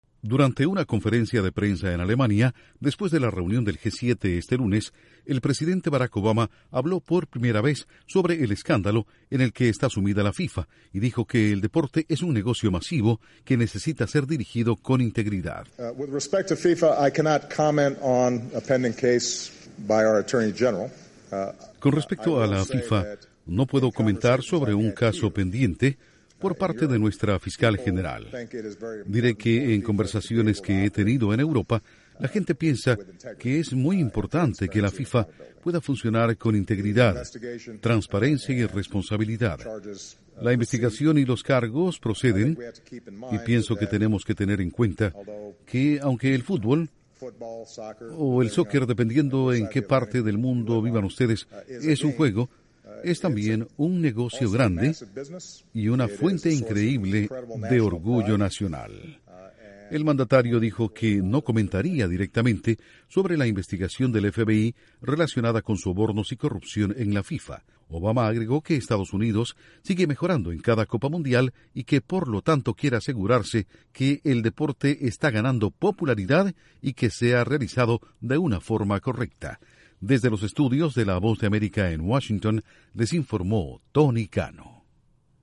El presidente Barack Obama habla en Alemania, por primera vez, con respecto al escándalo de la FIFA.